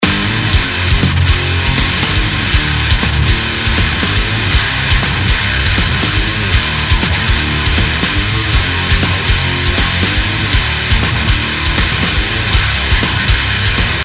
Наиграйте плиз гитарный луп
Наиграйте плиз гитарный лупчик :crazy: связка аккордов Em-G-Am-H7 (100bpm) желательно 2 дабла, буду очень благодарен !!! надеюсь на вашу помощь...